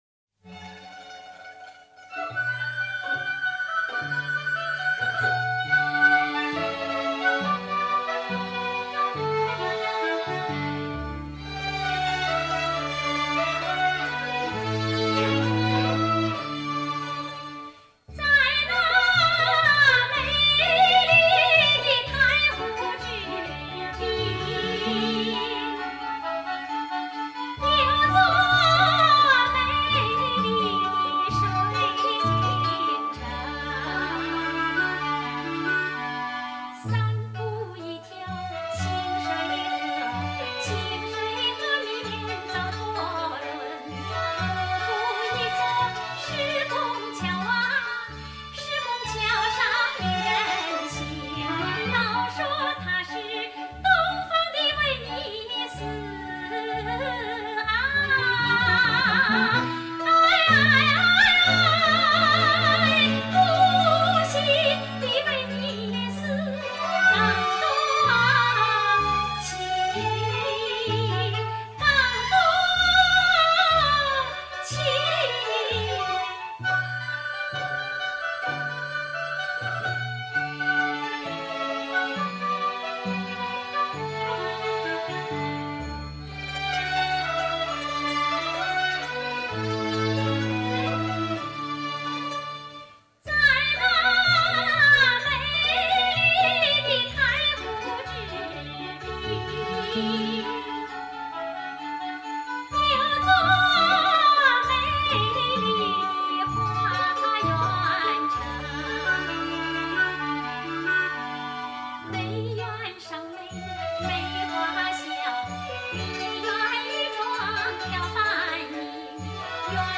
[13/4/2018]万馥香、于淑珍演唱的《美丽的无锡景》-欣赏两位歌唱家不同的演唱风格